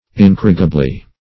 incorrigibly - definition of incorrigibly - synonyms, pronunciation, spelling from Free Dictionary Search Result for " incorrigibly" : The Collaborative International Dictionary of English v.0.48: Incorrigibly \In*cor"ri*gi*bly\, adv.